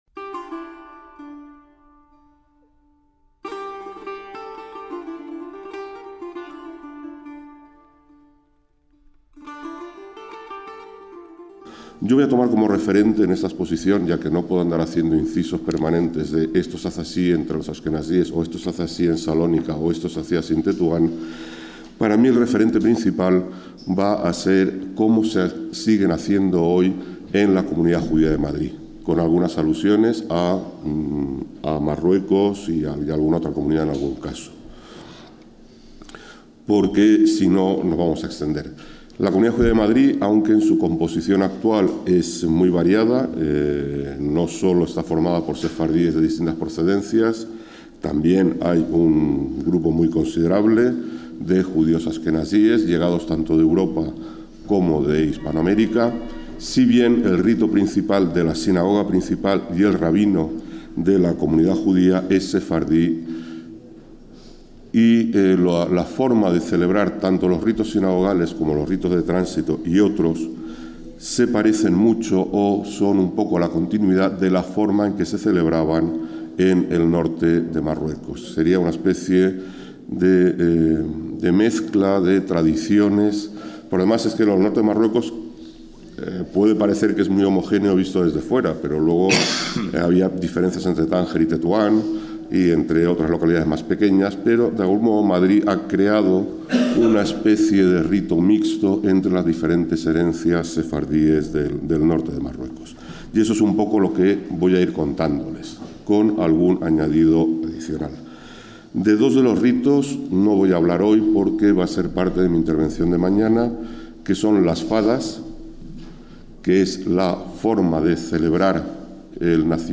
DESDE LAS X JORNADAS SEFARDÍES EN LA RIOJA